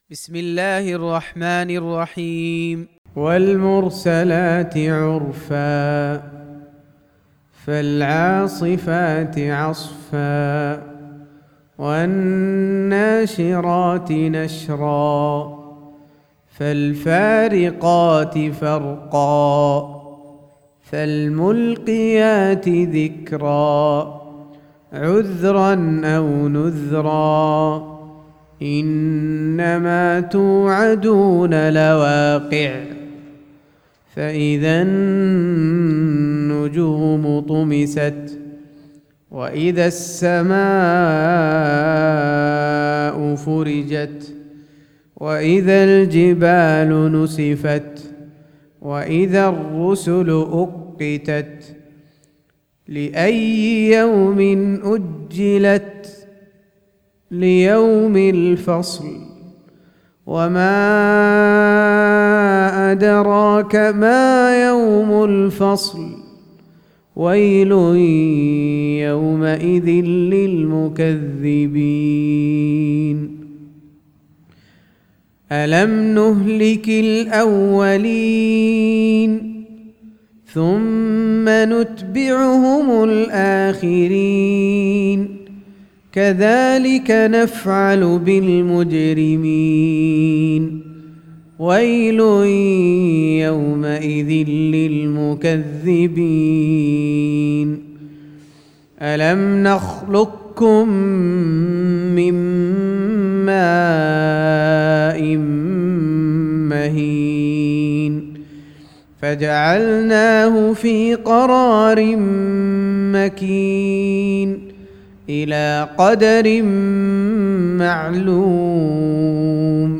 Surah Sequence تتابع السورة Download Surah حمّل السورة Reciting Murattalah Audio for 77. Surah Al-Mursal�t سورة المرسلات N.B *Surah Includes Al-Basmalah Reciters Sequents تتابع التلاوات Reciters Repeats تكرار التلاوات